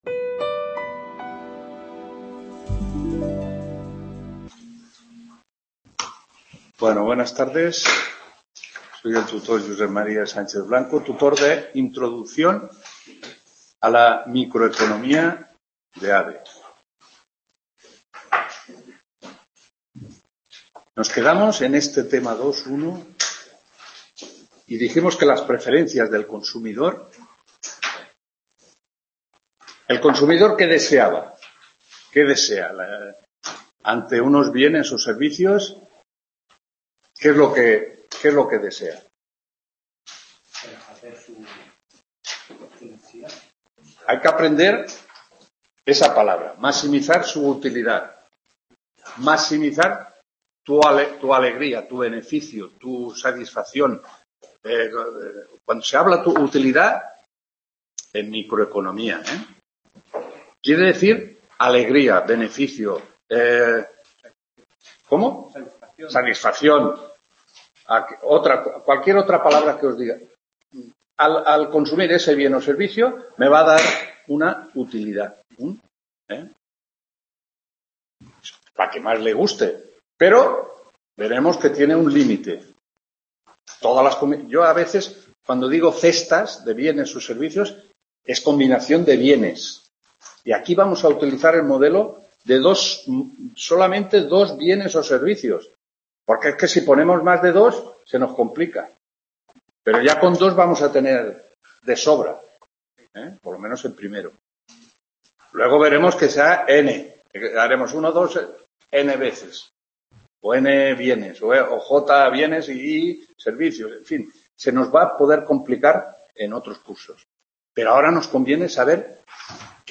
3ª TUTORÍA INTRODUCCIÓN A LA MICROECONOMÍA (ADE)